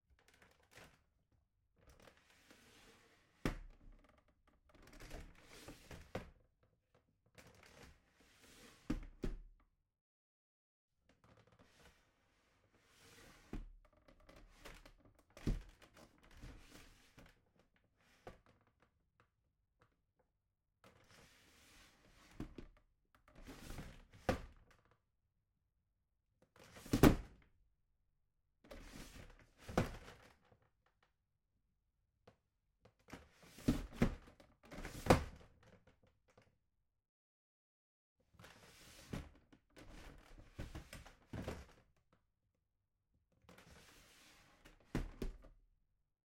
На этой странице собраны уникальные звуки комода: скрипы ящиков, стук дерева, движение механизмов.
Звуковая серия взаимодействия с комодом